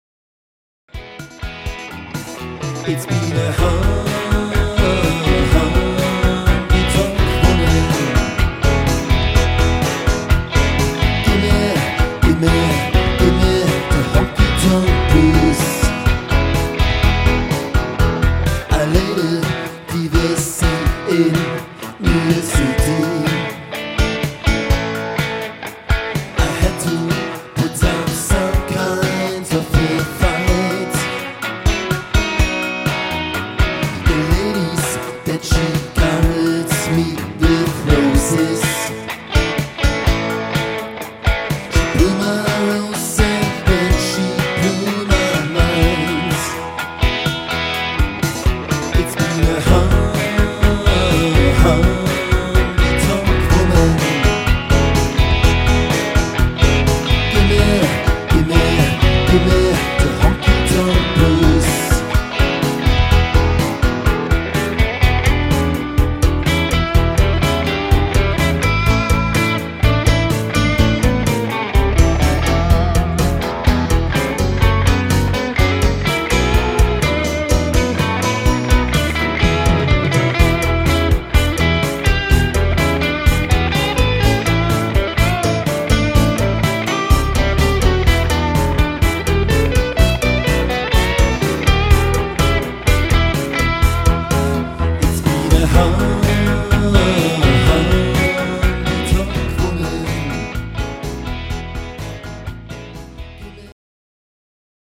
• Coverband